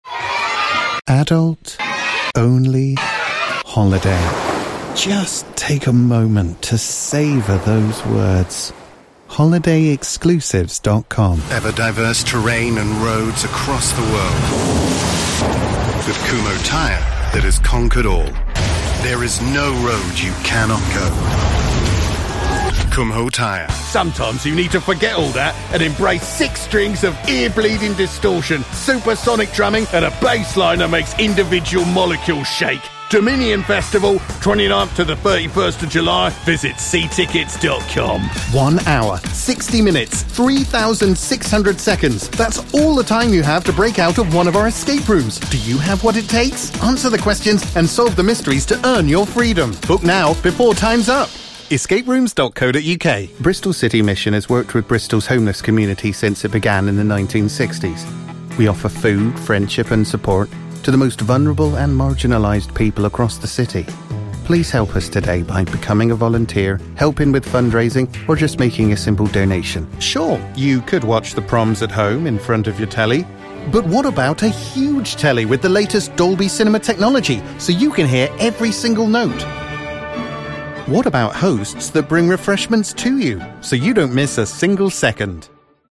Hallo, ich bin ein britischer männlicher Sprecher mit einer warmen und vertrauenswürdigen Stimme, die sich für Unternehmenspräsentationen und E-Learning eignet, aber auch die Bandbreite hat, um an skurrilen Werbespots, Animationen und Videospielen zu arbeiten.
Sprechprobe: Werbung (Muttersprache):
Hi, I'm a male British voiceover with a warm and trustworthy voice suitable for corporate narration and elearning but with the range to also work on quirky commercials, animation, and video games.